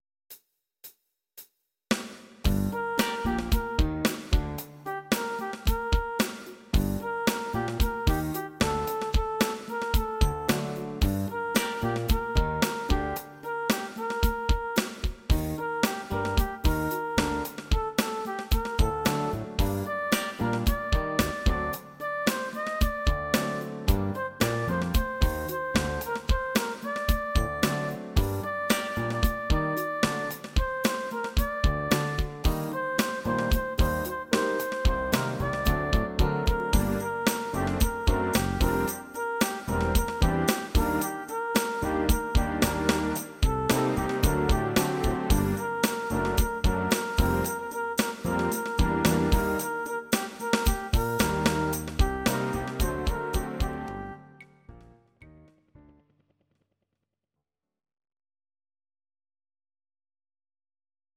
Audio Recordings based on Midi-files
Pop, Jazz/Big Band, 2010s